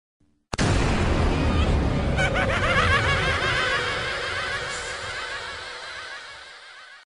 Sound Buttons: Sound Buttons View : COD Zombies Evil Laugh
cod-zombies-evil-laugh.mp3